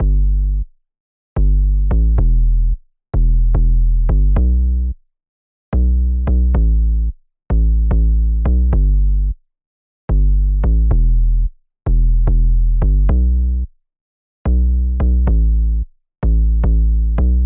标签： 110 bpm Hip Hop Loops Drum Loops 2.94 MB wav Key : Unknown FL Studio
声道立体声